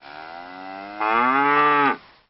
UI_Point_Cow.ogg